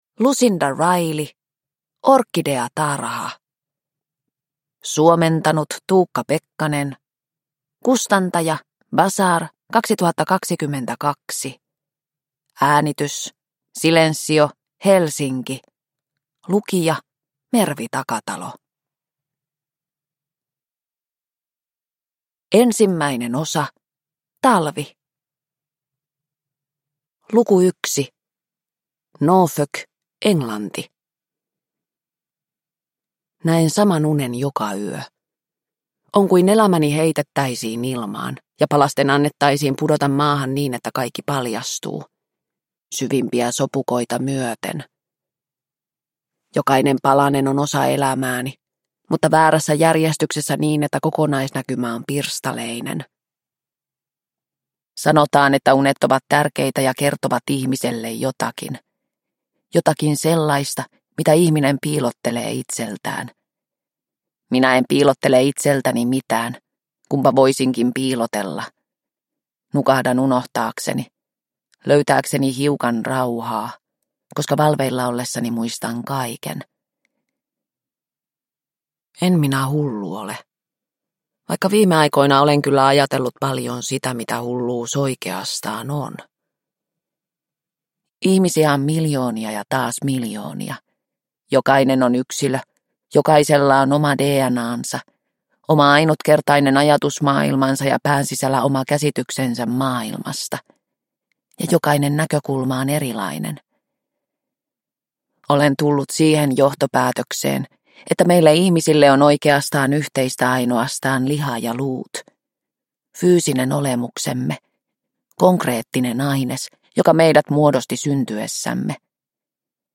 Orkideatarha – Ljudbok – Laddas ner